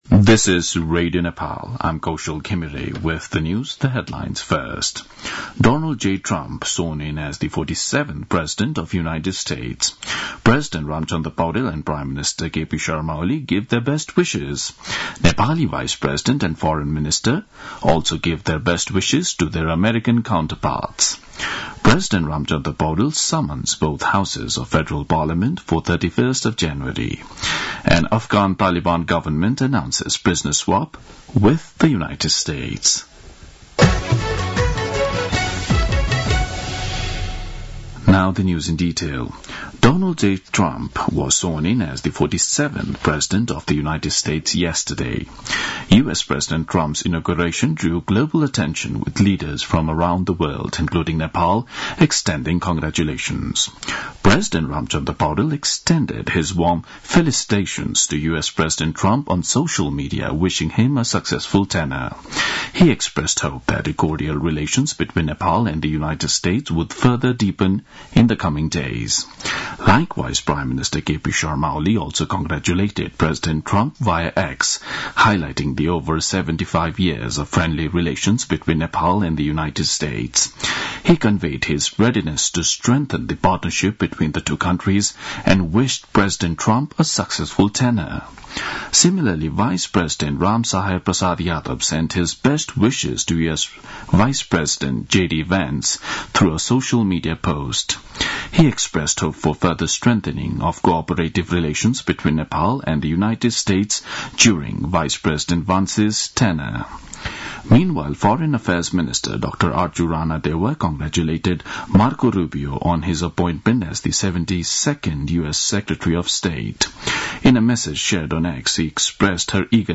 दिउँसो २ बजेको अङ्ग्रेजी समाचार : ९ माघ , २०८१